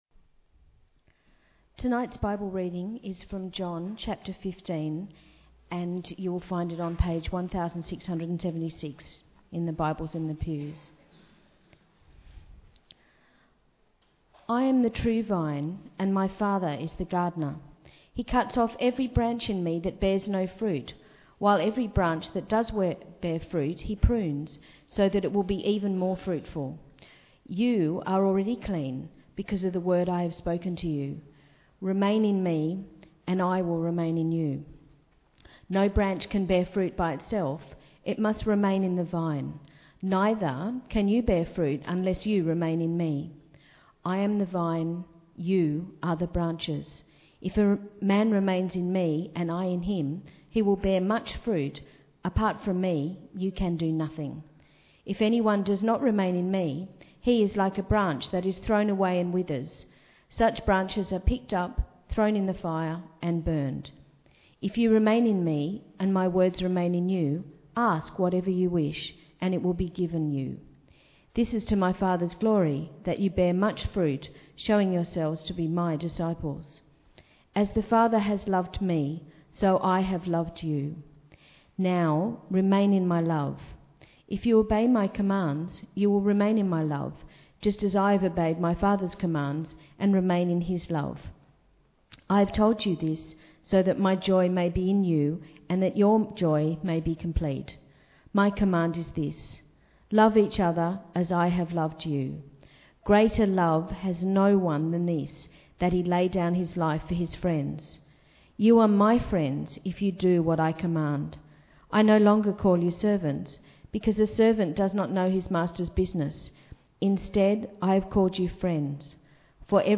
Bible Text: John 15: 1-17 | Preacher: